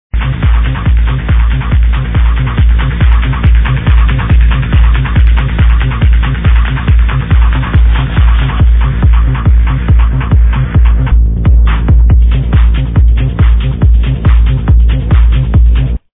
Please help me to find this technotune. thanx!